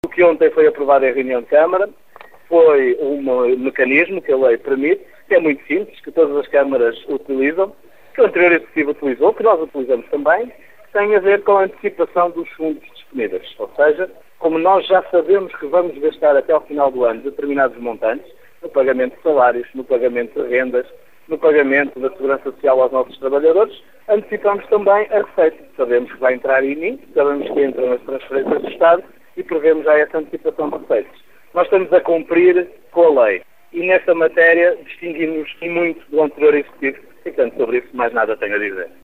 Em reação, o presidente da câmara de Caminha diz que é mais uma das muitas mentiras do PSD. O autarca diz que agora Caminha cumpre a lei.